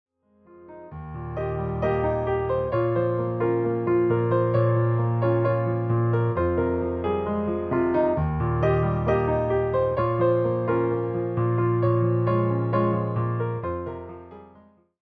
reimagined as solo piano arrangements.